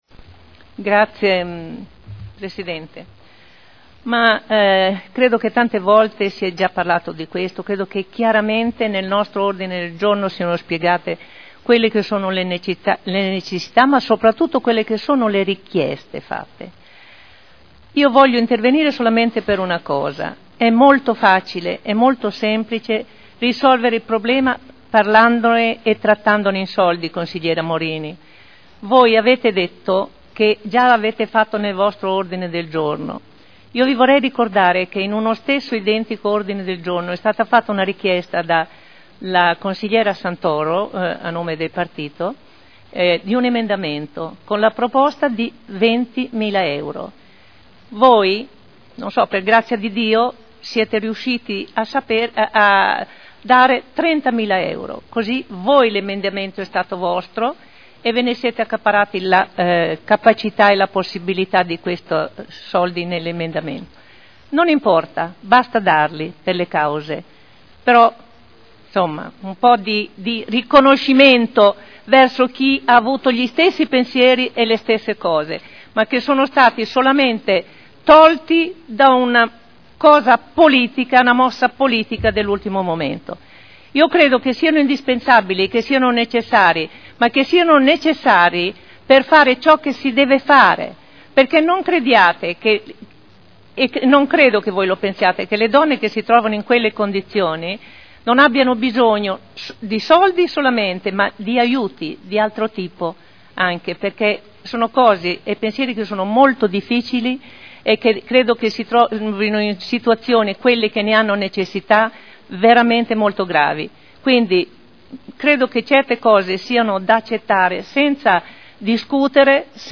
Olga Vecchi — Sito Audio Consiglio Comunale